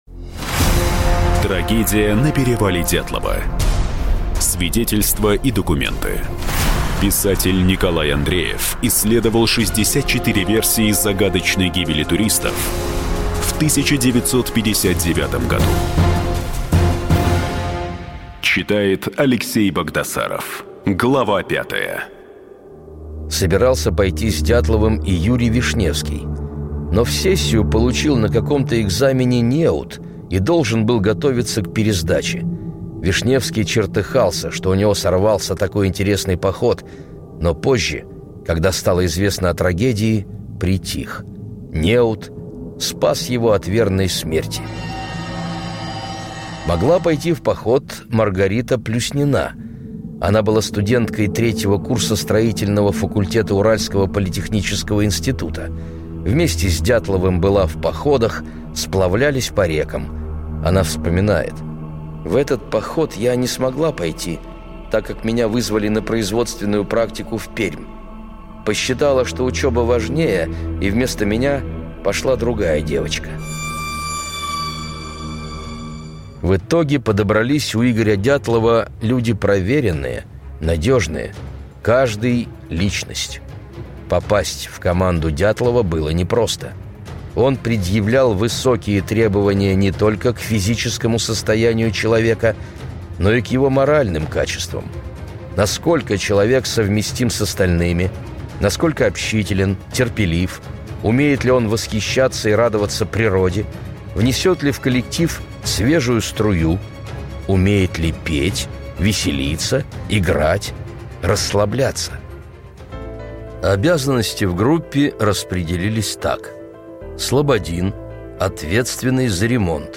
Аудиокнига Трагедия на перевале Дятлова: 64 версии загадочной гибели туристов в 1959 году. Часть 5 и 6 | Библиотека аудиокниг